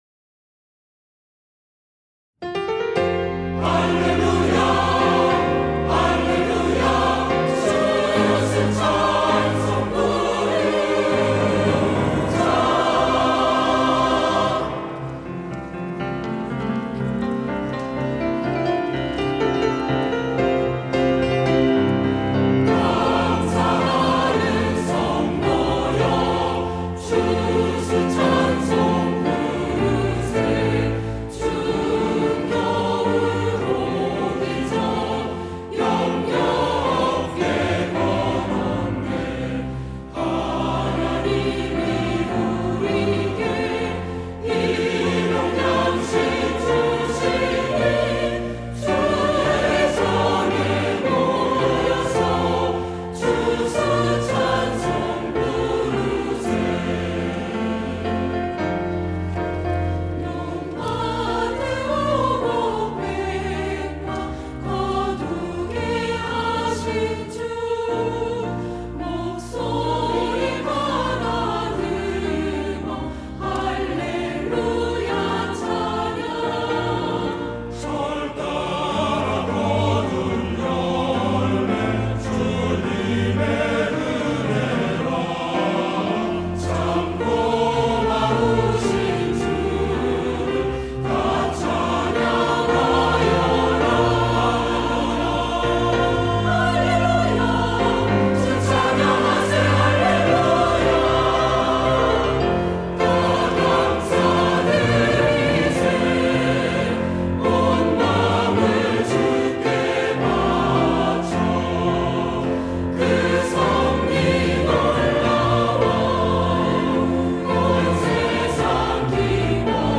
시온(주일1부) - 추수감사절 찬송
찬양대